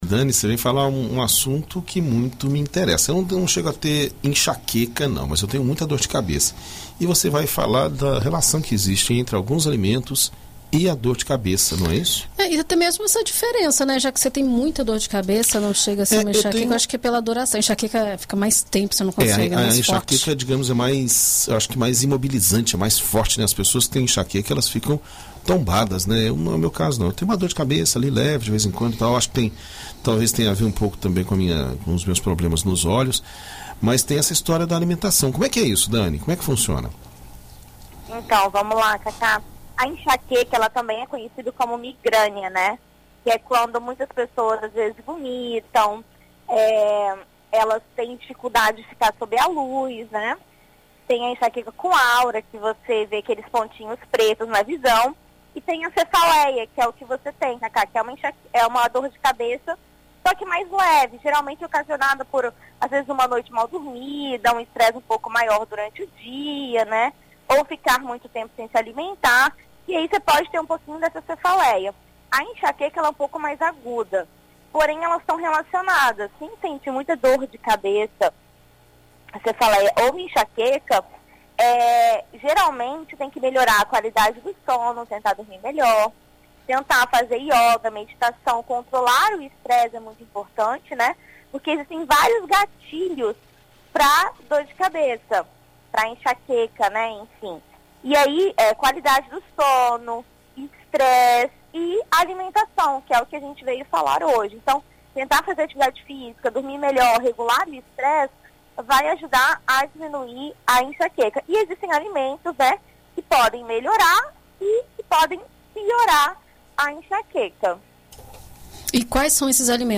Na coluna Viver Bem desta quarta-feira (18), na BandNews FM Espírito Santo